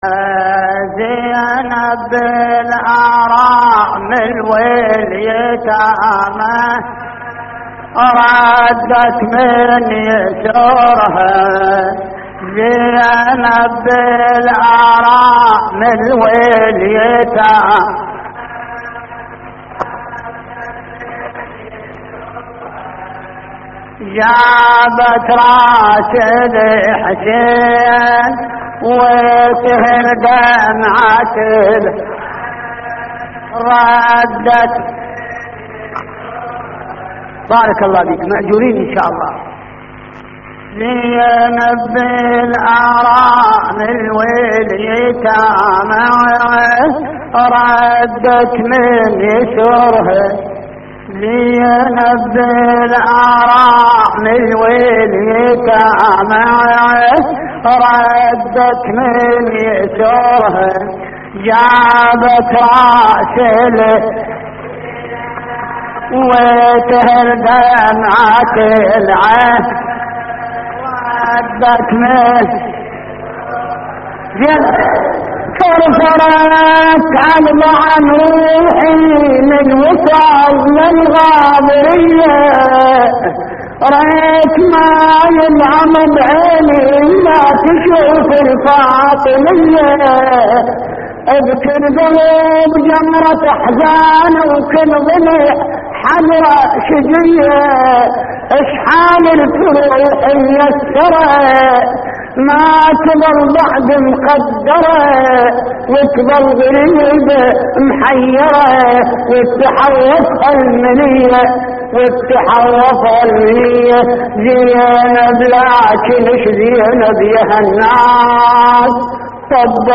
تحميل : زينب بالأرامل واليتامى ردت من يسرها / الرادود حمزة الصغير / اللطميات الحسينية / موقع يا حسين